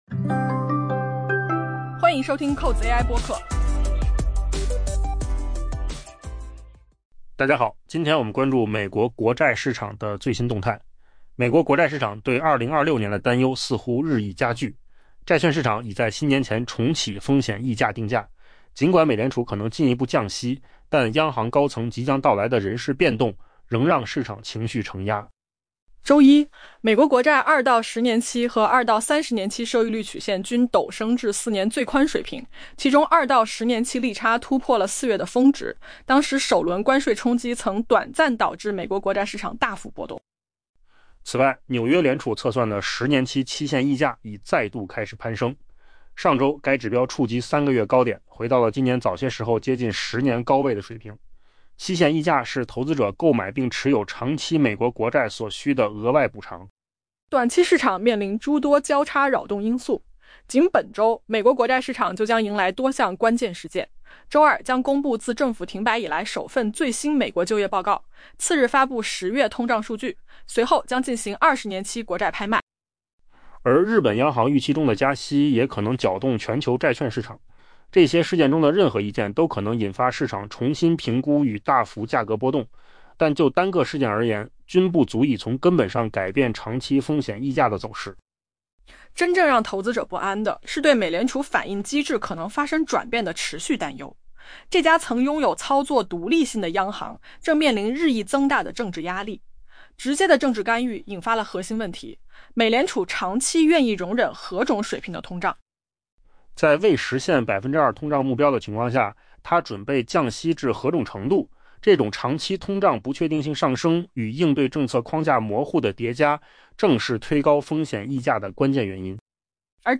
AI 播客：换个方式听新闻 下载 mp3 音频由扣子空间生成 美国国债市场对 2026 年的担忧似乎日益加剧， 债券市场已在新年前重启风险溢价定价 。